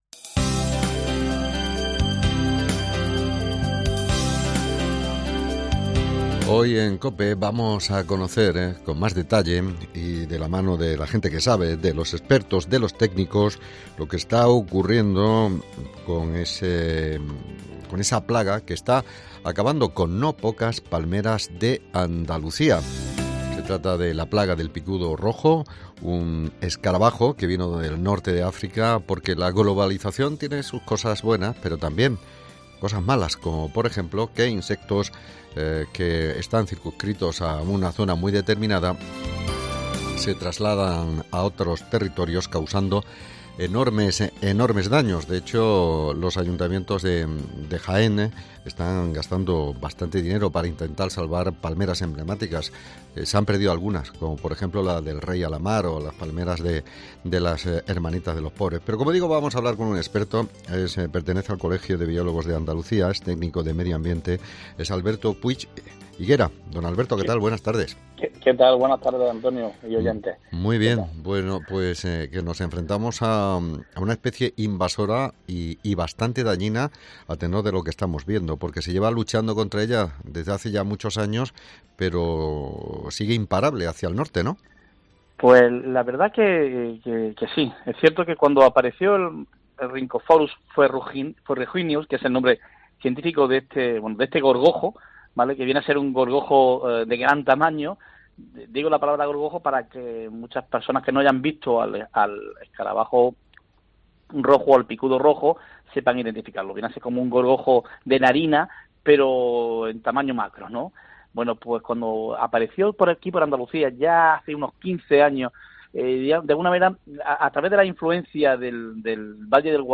Hablamos con el biólogo